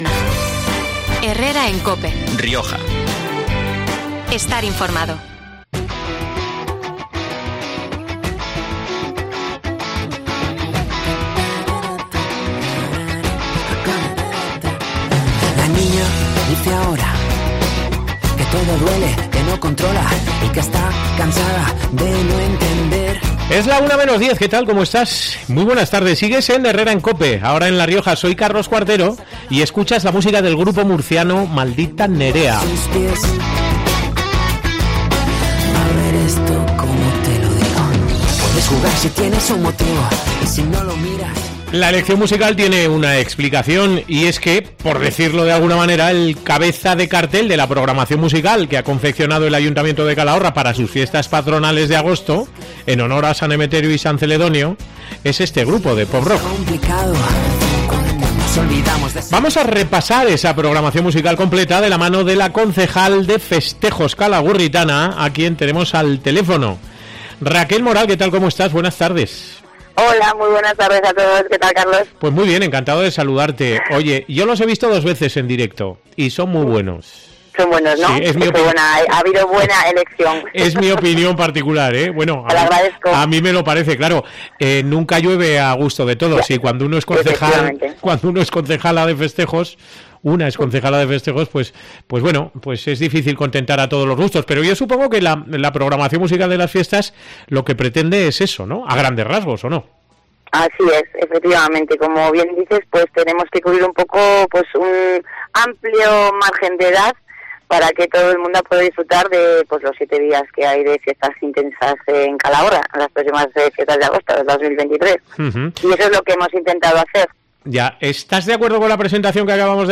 La concejal de Festejos de Calahorra, Raquel Moral, ha dado a conocer en COPE Rioja los grupos y orquestas que actuarán en las próximas fiestas patronales de agosto, en honor a San Emeterio y San Celedonio.